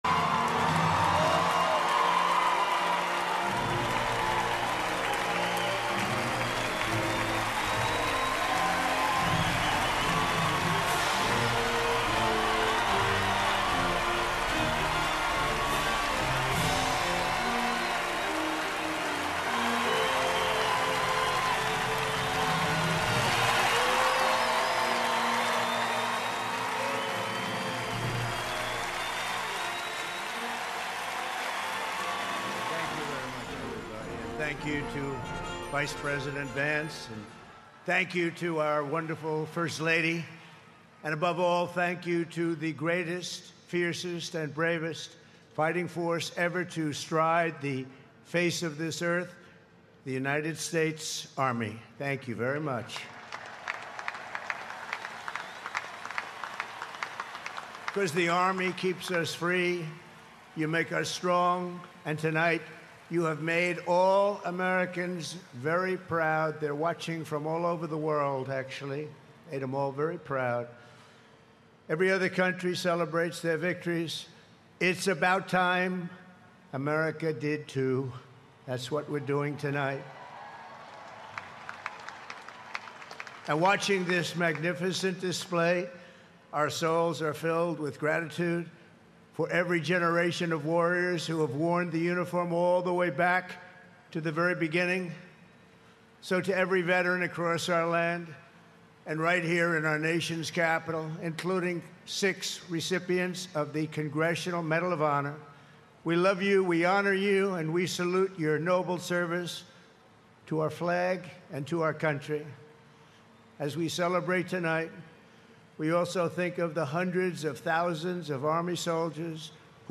President Donald Trumps Full Remarks at the 250th Anniversary of the US Army Grand Military Parade